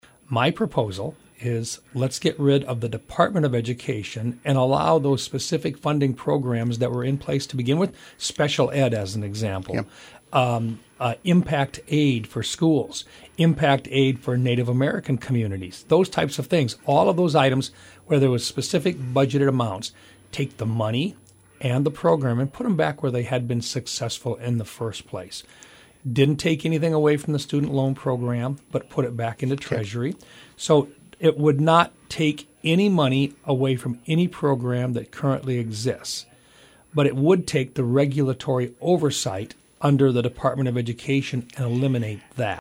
Recap of Senator Mike Rounds’ interview with Hub City Radio